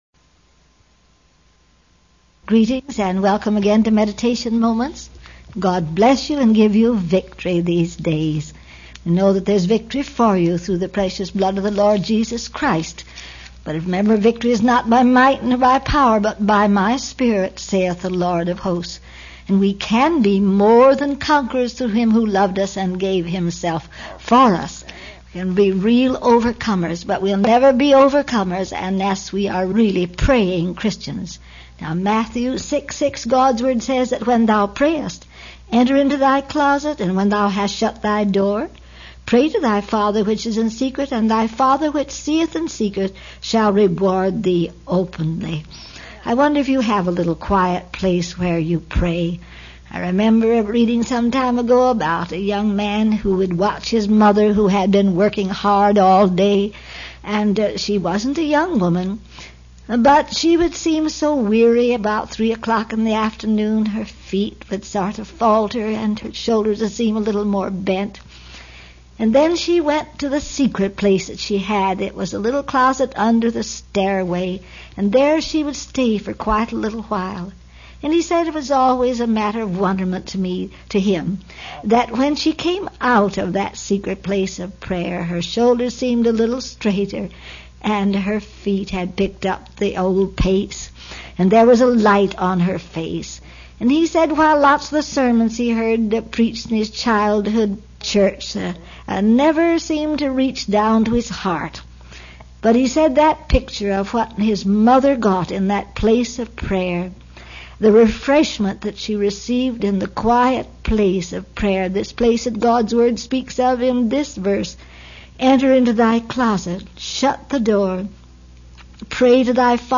This was a transcript of a Meditation Moments #79 broadcast.